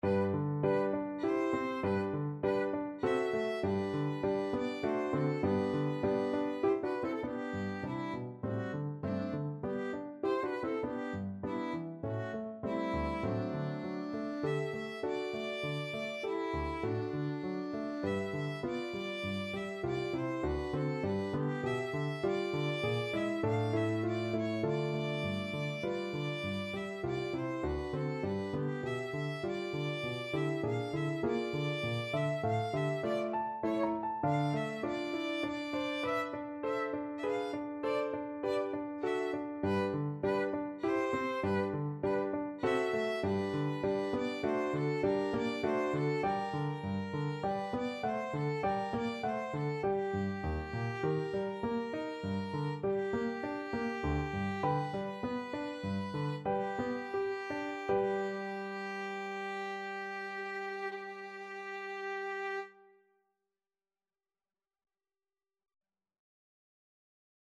Violin
G major (Sounding Pitch) (View more G major Music for Violin )
~ = 100 Allegretto grazioso (quasi Andantino) (View more music marked Andantino)
D5-F6
3/4 (View more 3/4 Music)
Classical (View more Classical Violin Music)
brahms_sym2_3rd_mvt_VLN.mp3